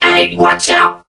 mech_crow_get_hit_06.ogg